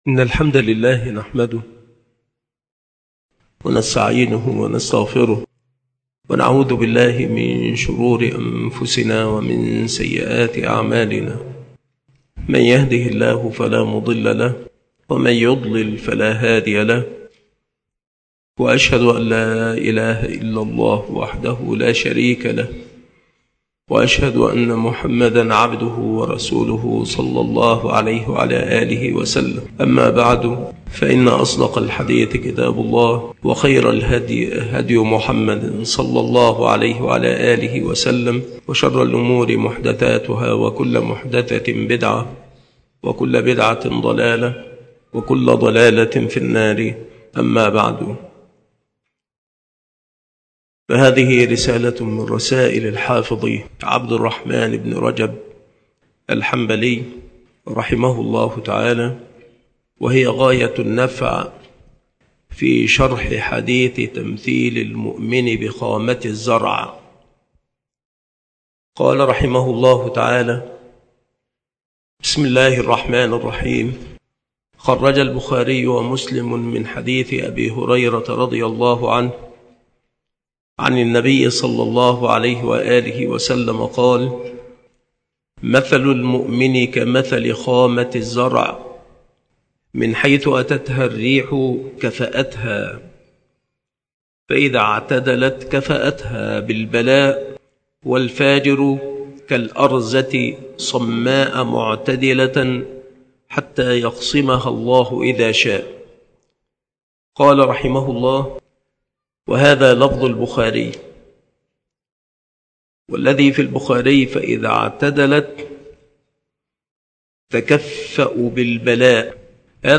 شروح الحديث
مكان إلقاء هذه المحاضرة بالمسجد الشرقي - سبك الأحد - أشمون - محافظة المنوفية - مصر